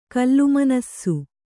♪ kallumanassu